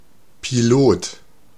Ääntäminen
Synonyymit Kapitän Ääntäminen Tuntematon aksentti: IPA: /pi'loːt/ IPA: /pi'loːtən/ Haettu sana löytyi näillä lähdekielillä: saksa Käännös Ääninäyte Substantiivit 1. pilot US Artikkeli: der .